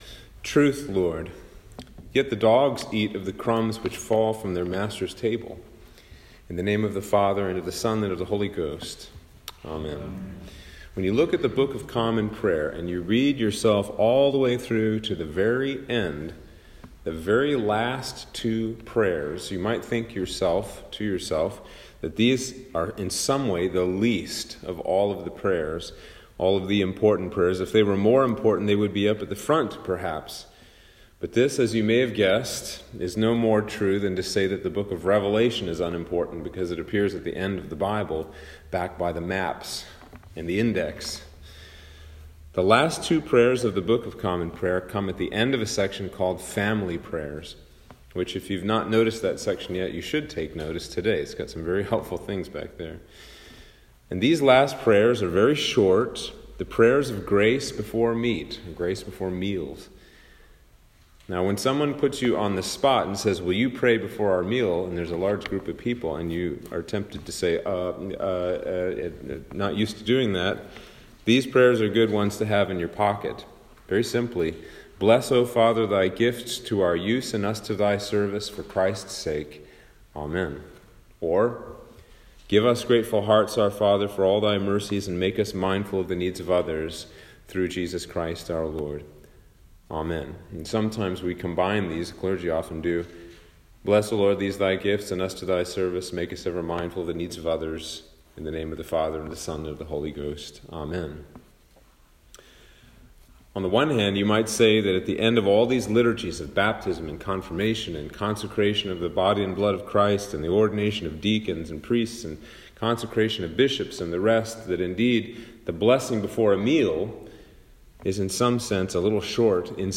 Sermon for Lent 2 - 2022